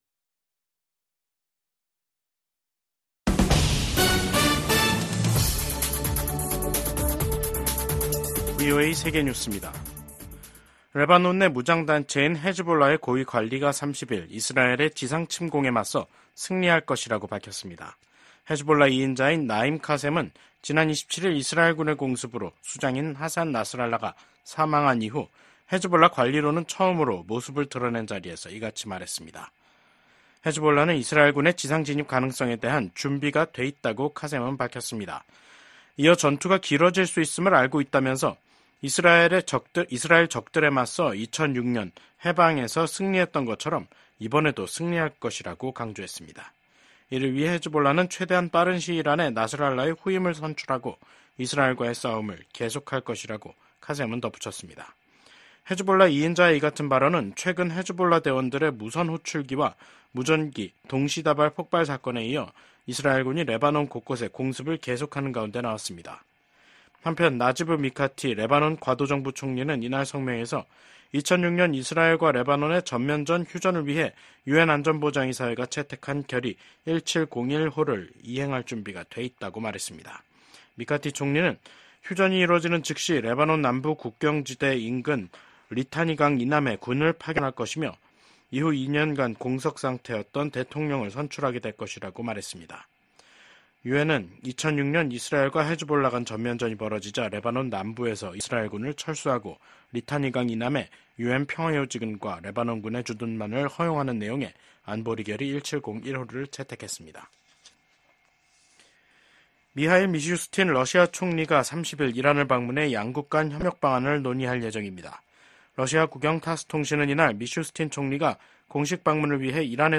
VOA 한국어 간판 뉴스 프로그램 '뉴스 투데이', 2024년 9월 30일 3부 방송입니다. 미국 국무장관이 북한을 압박해 러시아에 대한 무기 제공을 중단시켜야 한다고 강조했습니다. 미 국무부는 북한이 사실상 핵보유국이라는 국제원자력기구(IAEA) 수장의 발언과 관련해 한반도의 완전한 비핵화 목표를 다시 한 번 확인했습니다. 한국 정부는 북한 비핵화 목표를 부정한 러시아 외무장관의 발언에 대해 무책임하다고 비판했습니다.